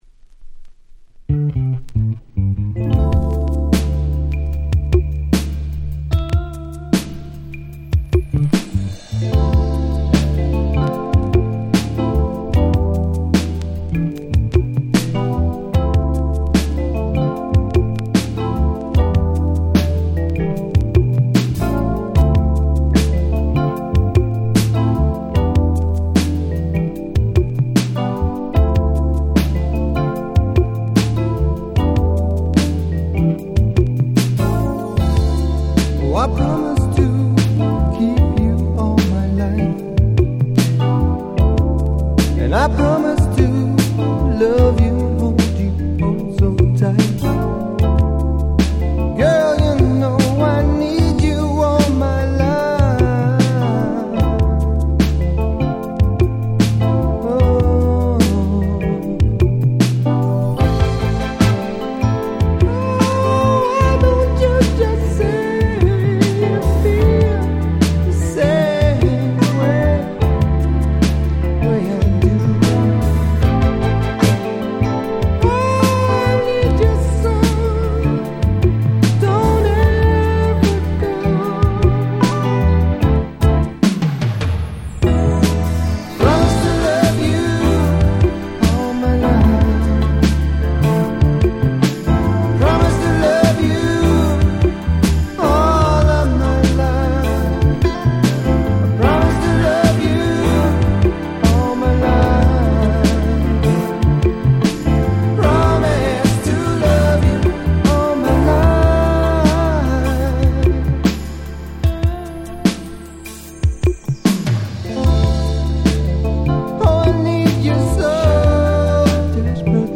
92' Super Nice UK R&B / UK Street Soul !!
90's R&B